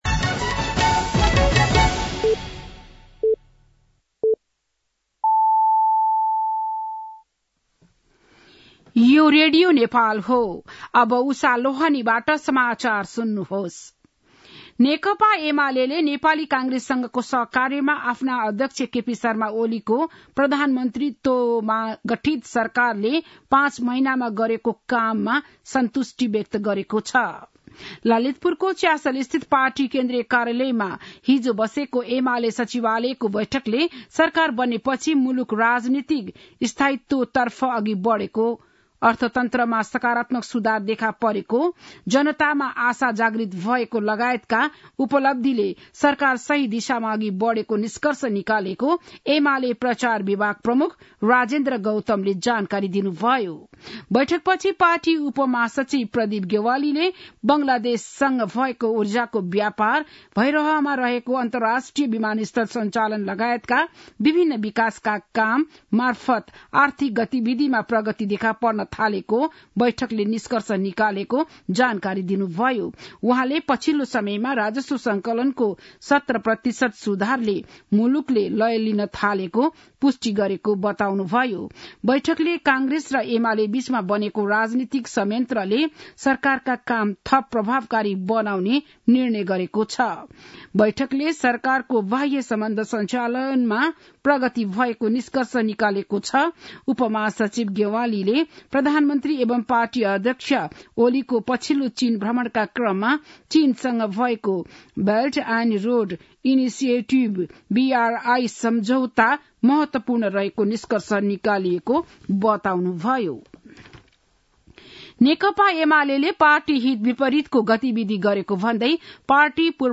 बिहान ११ बजेको नेपाली समाचार : १२ पुष , २०८१
11-am-Nepali-News-9-11.mp3